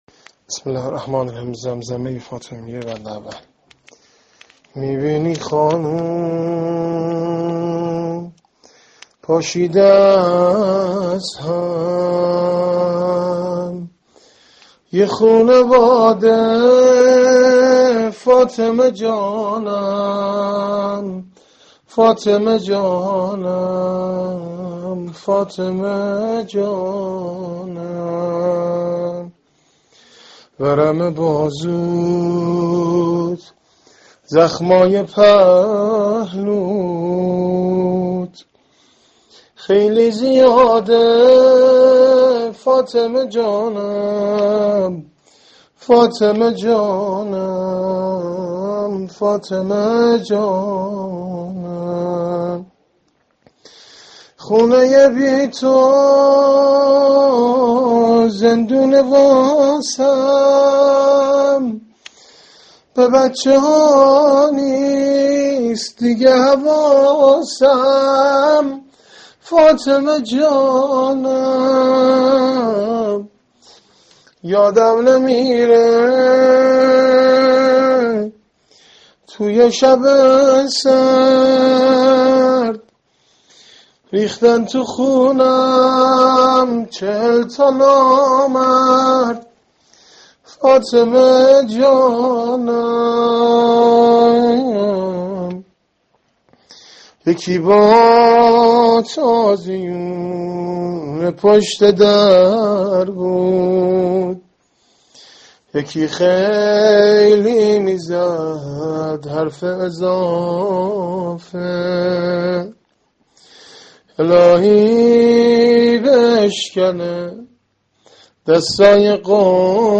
زمزمه ایام فاطمیه -(می بینی خانوم ، پاشیده از هم ، یه خونواده‌، (فاطمه جانم)(۳))